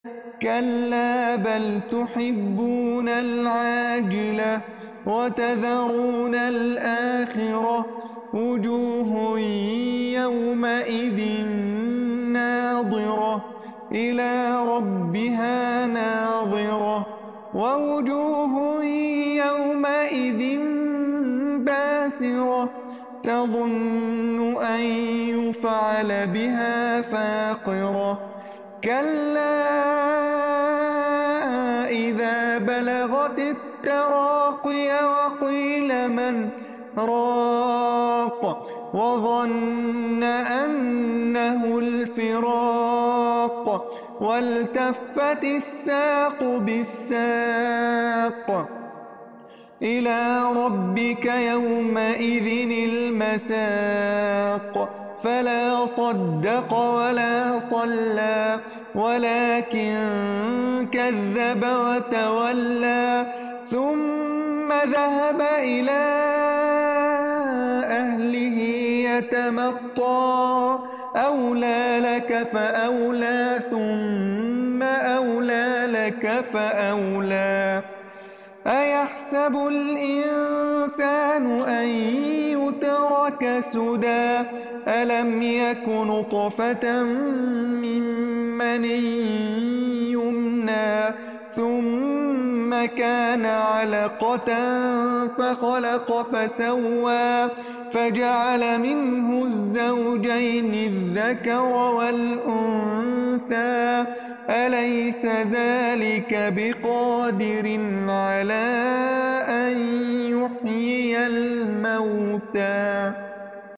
تلاوة من سورة القيامة، بصوتي sound effects free download